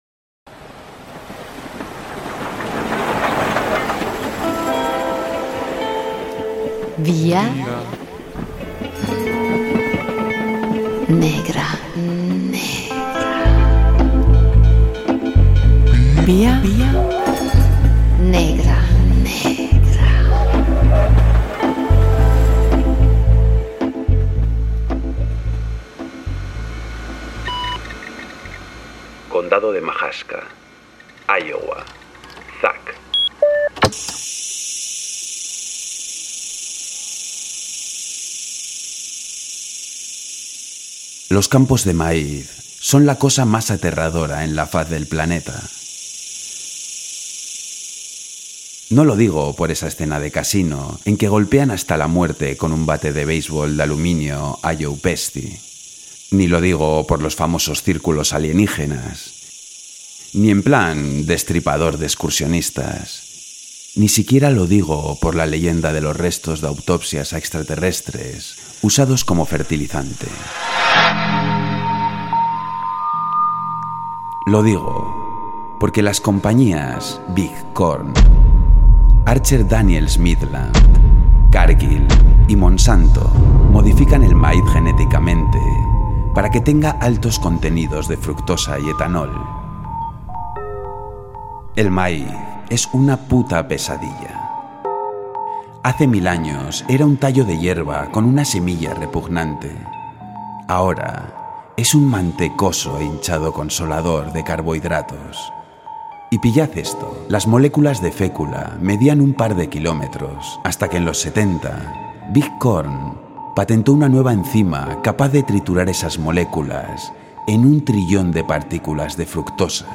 Audio: Vía Negra desgrana un fragmento de "Generación A" de Douglas Coupland.